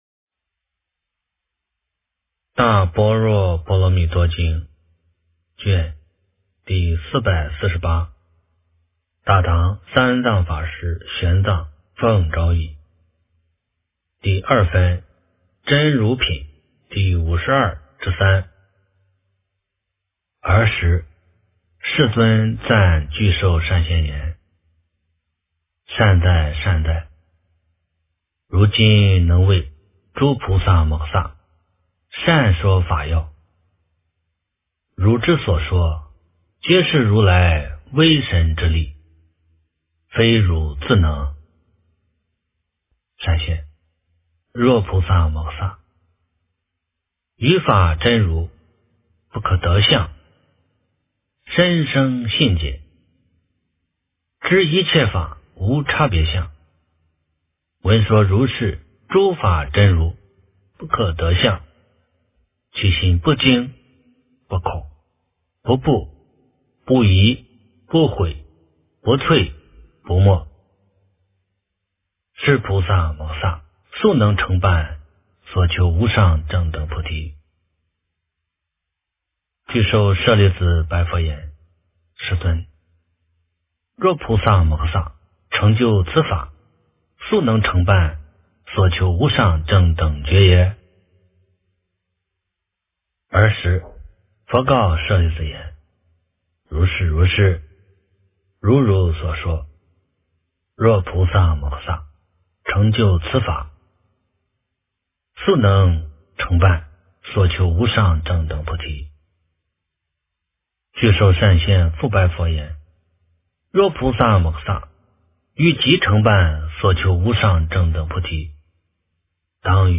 大般若波罗蜜多经第448卷 - 诵经 - 云佛论坛